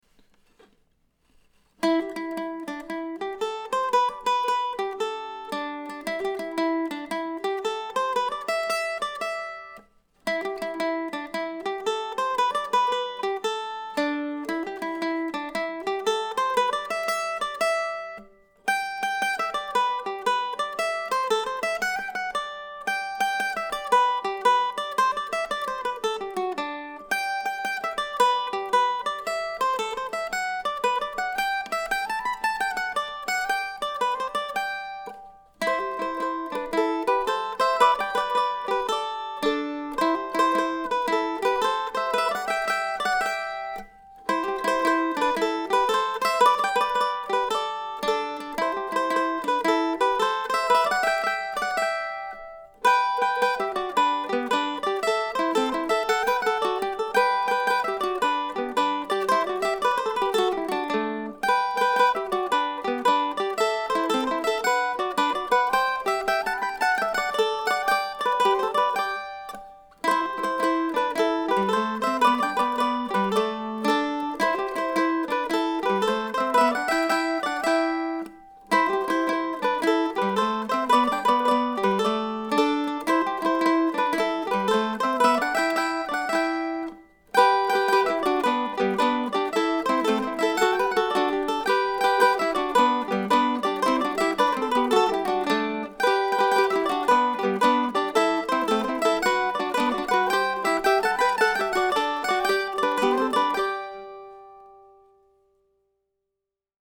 Today's tune is pretty straightforward and shouldn't provide too many obstacles to your playing or listening pleasure. It's another glass-half-full kind of tune with a big, bright G major chord at the end.